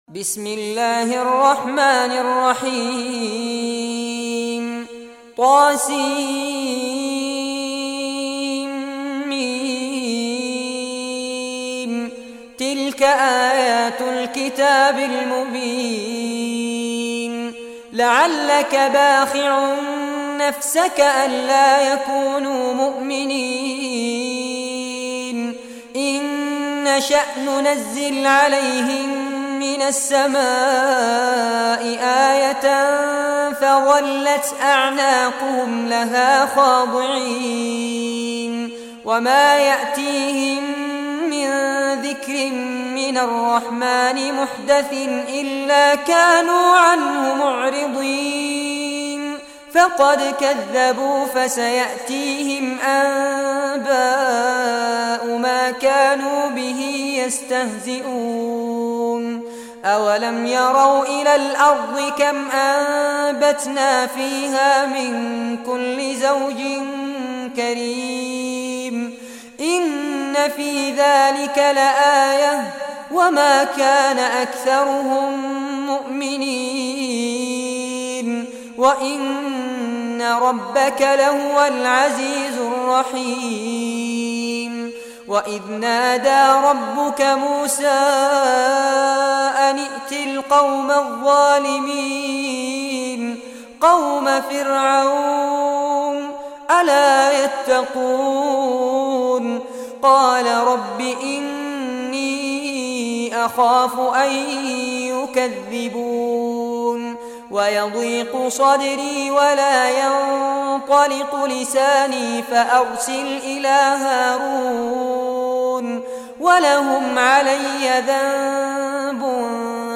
Surah Ash-Shuara Recitation by Fares Abbad
Surah Ash-Shuara, listen or play online mp3 tilawat / recitation in Arabic in the beautiful voice of Sheikh Fares Abbad.